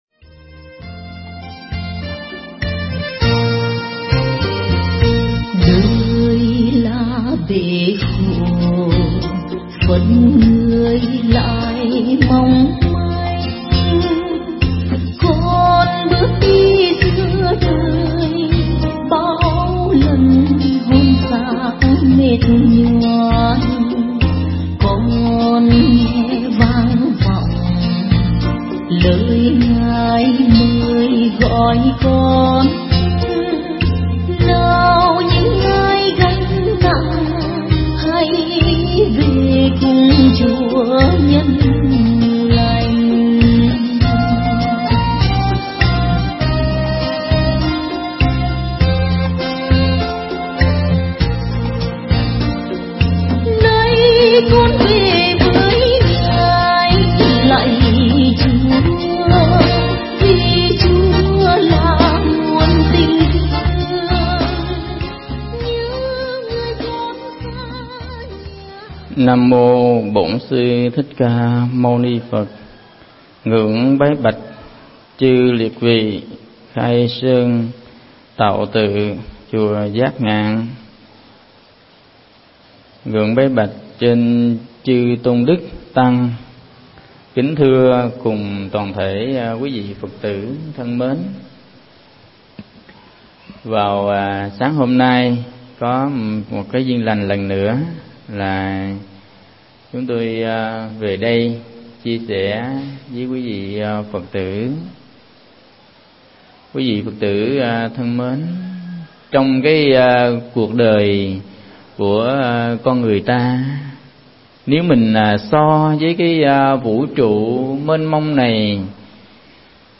Mp3 Pháp Thoại Nguồn gốc và ý nghĩa Kinh Pháp Hoa
giảng tại Đạo tràng chùa Giác Ngạn